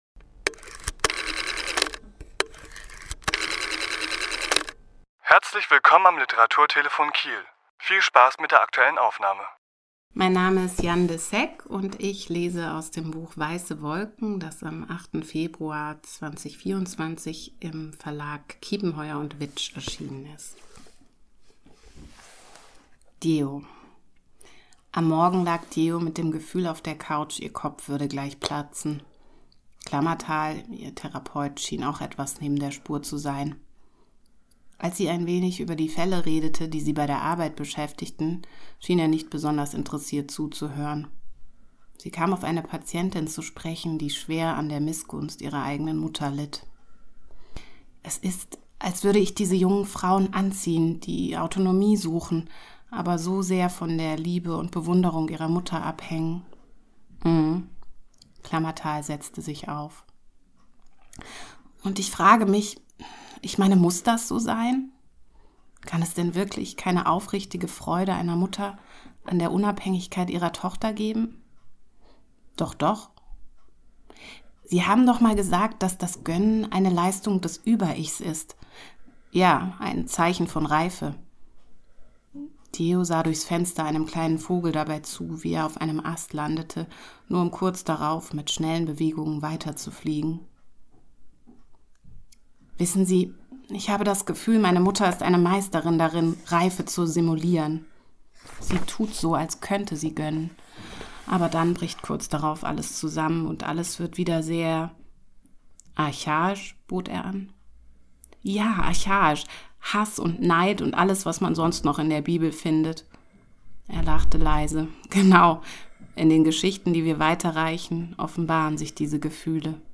Autor*innen lesen aus ihren Werken
Die Aufnahme entstand im Rahmen der LeseLounge am 21.2.2024 im Literaturhaus Schleswig-Holstein.